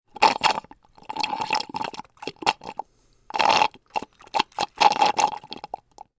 دانلود آهنگ آب 3 از افکت صوتی طبیعت و محیط
جلوه های صوتی